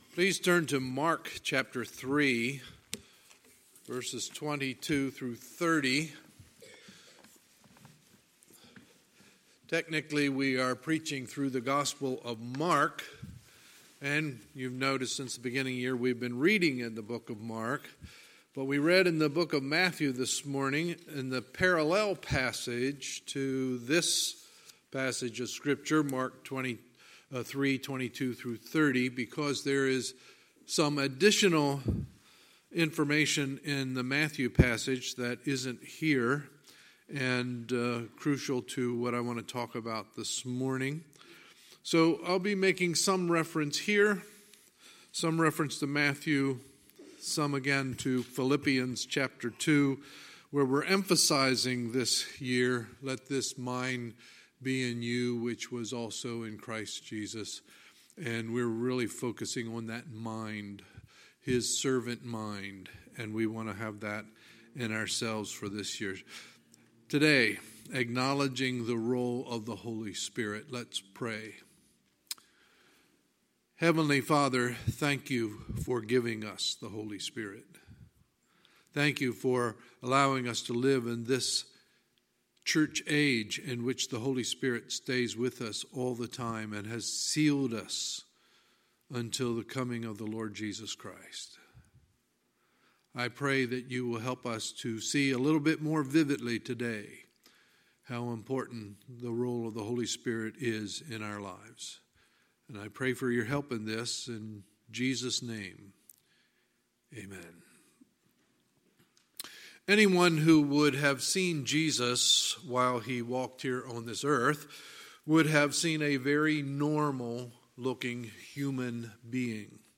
Sunday, March 17, 2019 – Sunday Morning Service